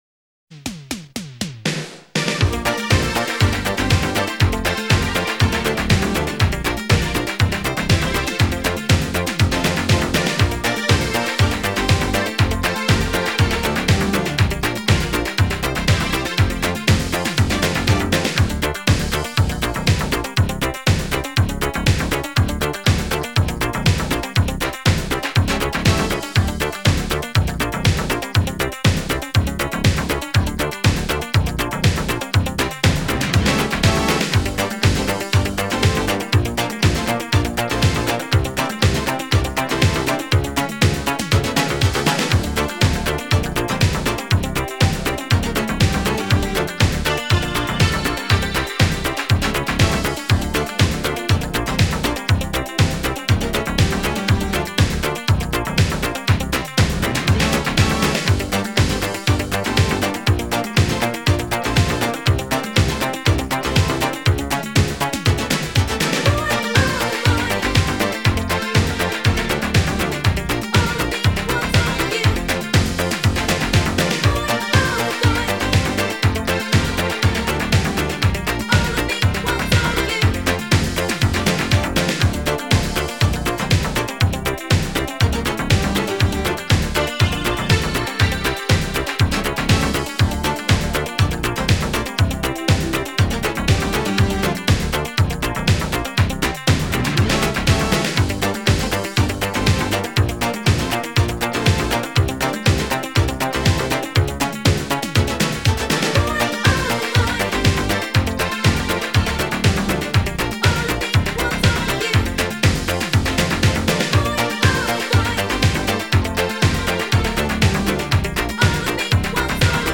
И еще на тему инструменталов